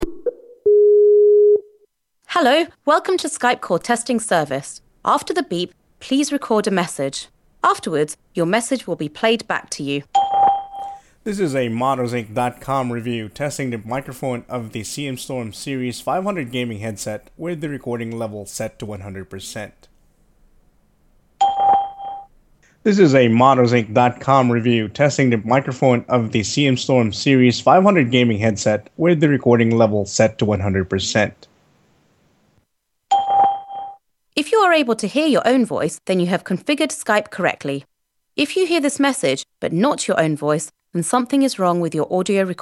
The microphone performance was also a lot better than expected, especially from a mainstream headset. A short demo audio below, recorded with Skype demonstrates the level of clarity that the Ceres 500 microphone provides.
Ceres500_skypetest.mp3